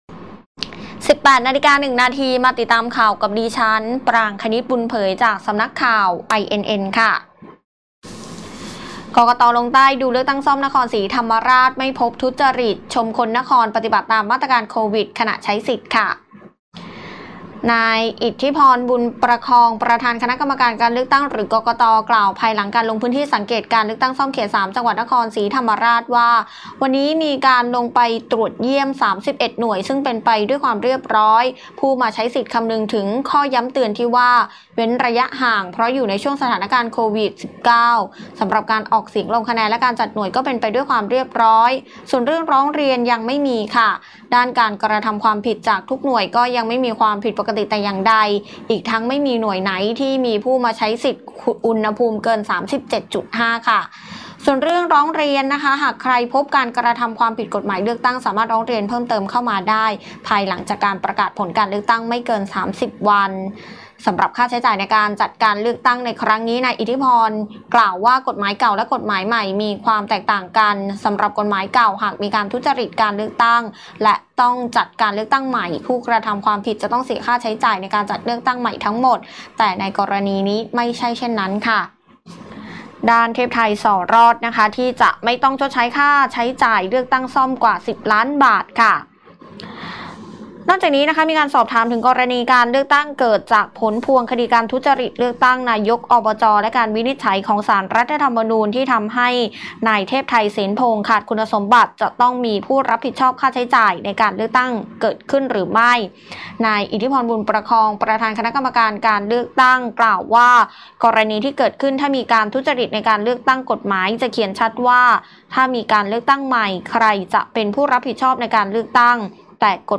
ข่าวต้นชั่วโมง 18.00 น.